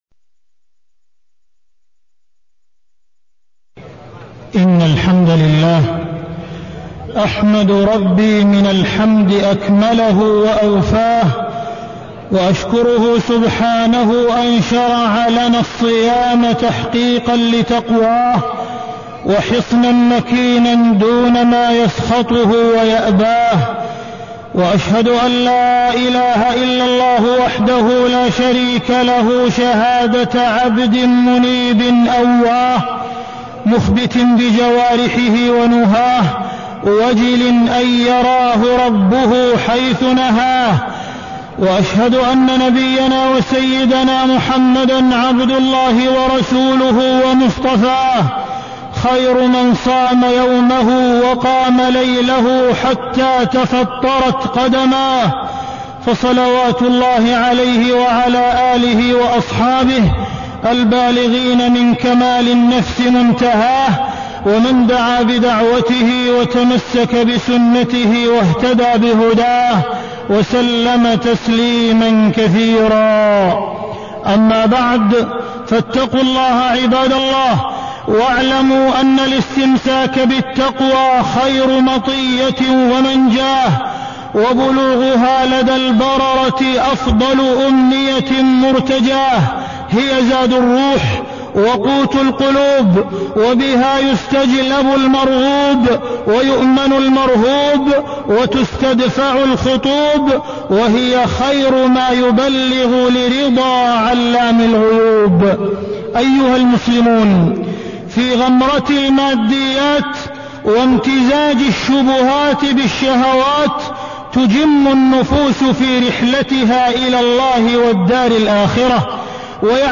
تاريخ النشر ١٣ رمضان ١٤٢٤ هـ المكان: المسجد الحرام الشيخ: معالي الشيخ أ.د. عبدالرحمن بن عبدالعزيز السديس معالي الشيخ أ.د. عبدالرحمن بن عبدالعزيز السديس شهر القرآن والصيام